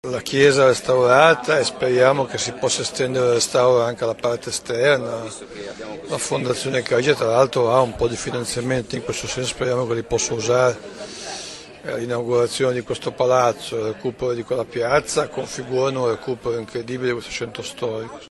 Burlando_su_Inaugurazione_6Nov2010.mp3